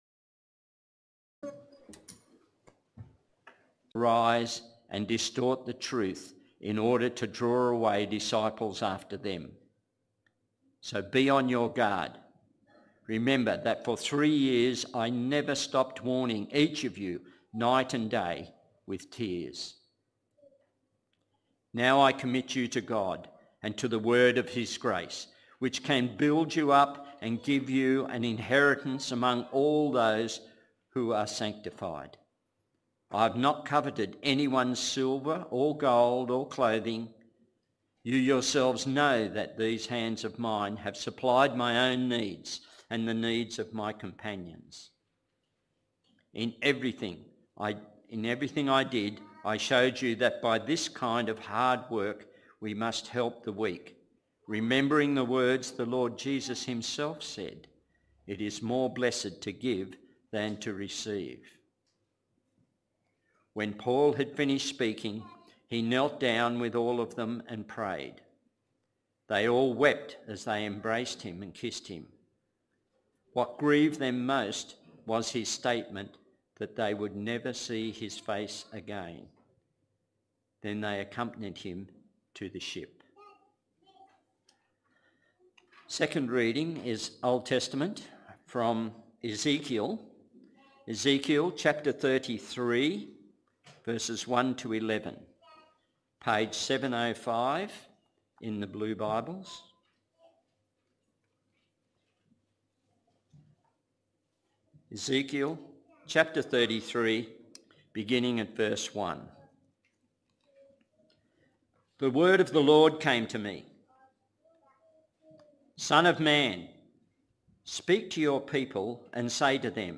Sermons | Dickson Baptist Church